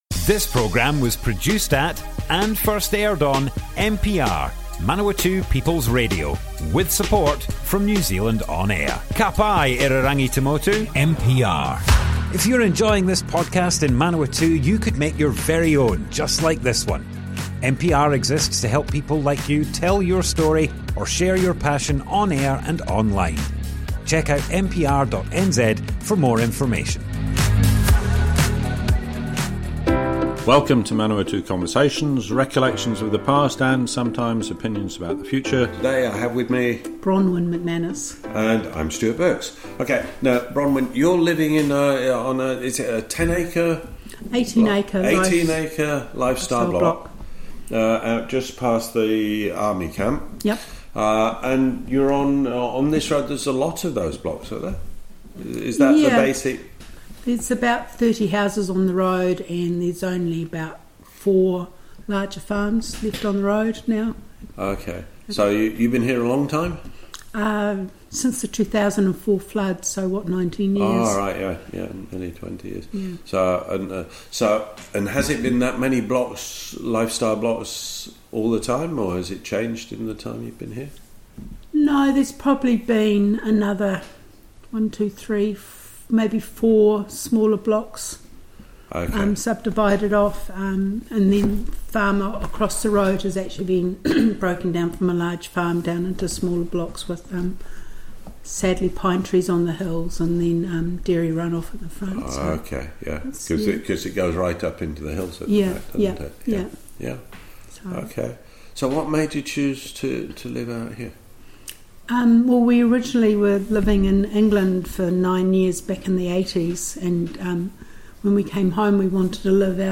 Manawatu Conversations More Info → Description Broadcast on Manawatu People's Radio, 5th September 2023.
oral history